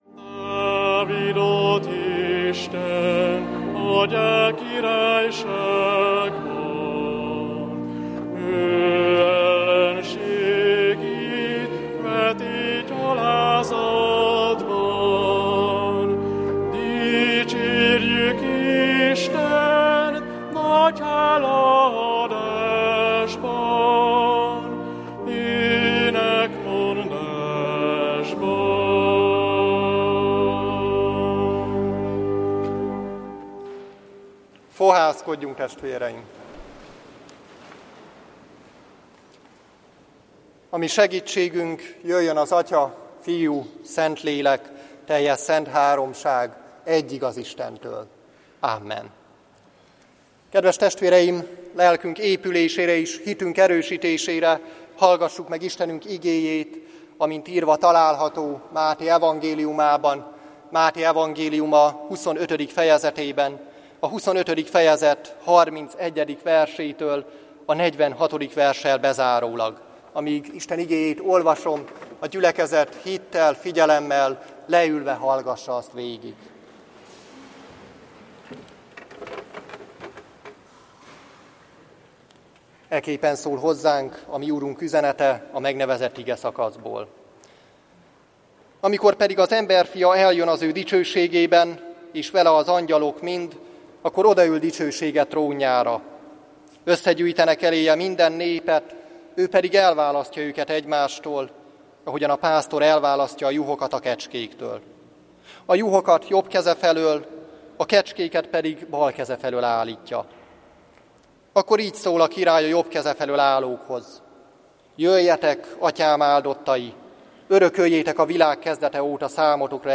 Hajdúböszörmény Kálvin téri Református Egyházközség